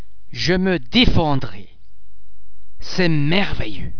The Emphatic and Affective Stress Point: ( Decided and determined by the speaker's psychological state)
Mullti-syllabic word starting with a consonant: Usually generated by emotions, indignation or surprise, the accent tends to fall on the very first syllable of the word that begin with a consonant.